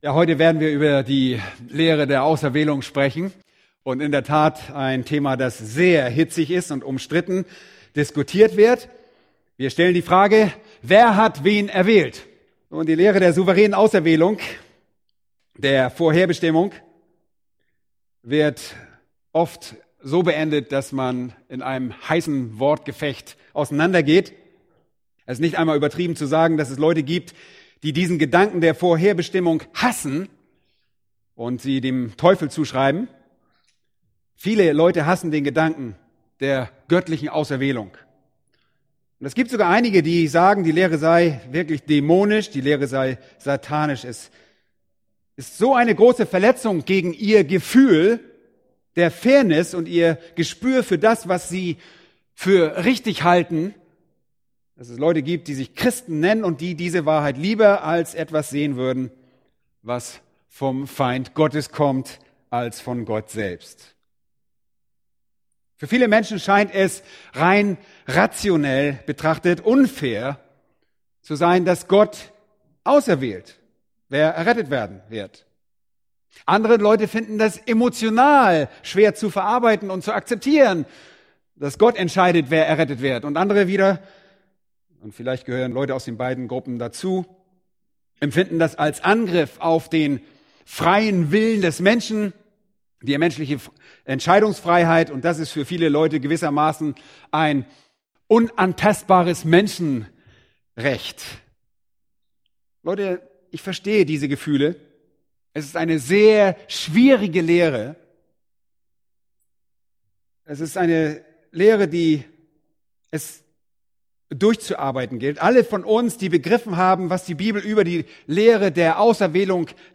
Eine predigt aus der serie "Weitere Predigten."